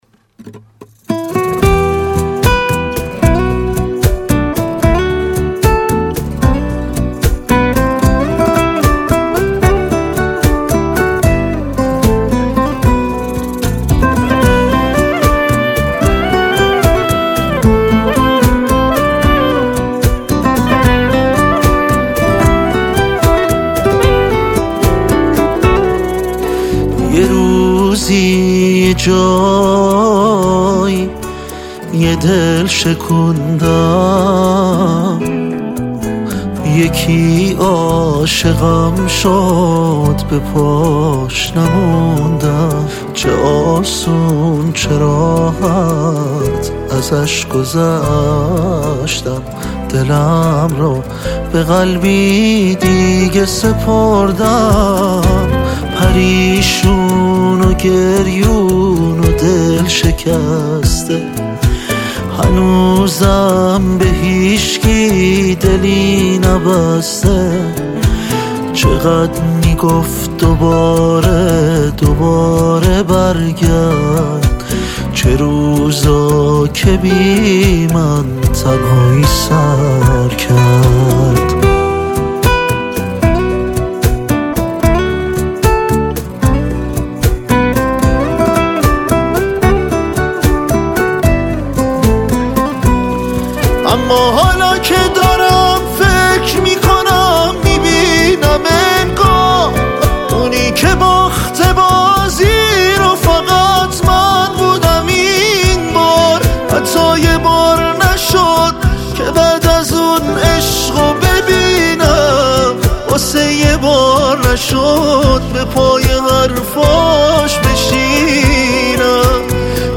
نسخه آکوستیک